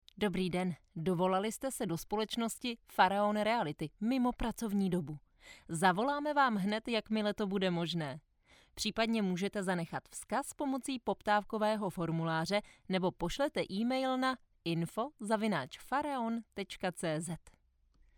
Profesionální voiceover do rozhlasového spotu (30s-50s)
Ve svém studiu natočím voiceover pro jakýkoliv váš projekt!
Hledáte mladý ženský hlas? Ozvěte se!
Příjemný a upoutává hlas.
Kvalitní zvuk, Bez šumu a bez ozvěny